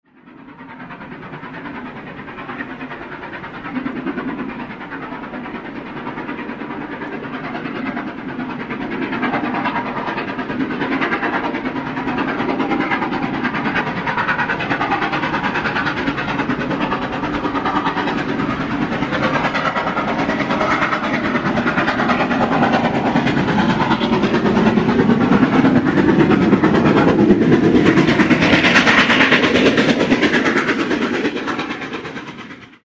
This third volume of tracks are all recordings of steam hauled trains on the main line heard from the lineside not all of which have appeared on the web site.